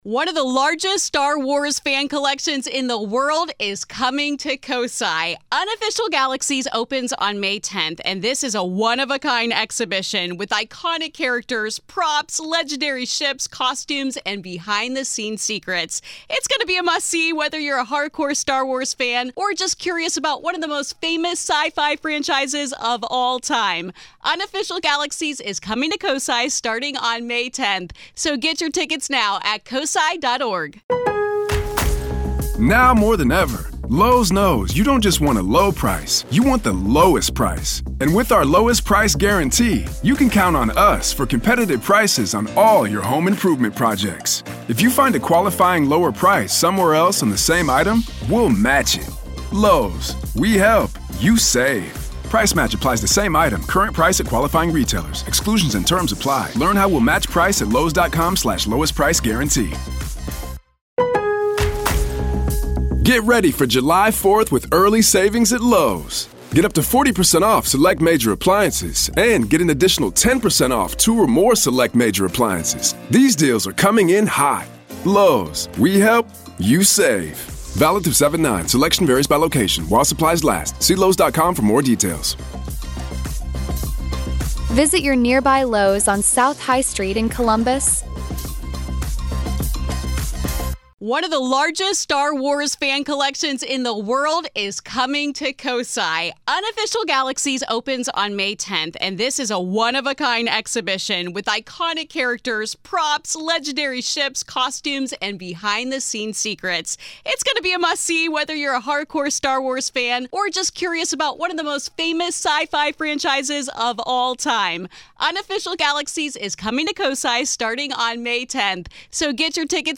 Today, we have a conversation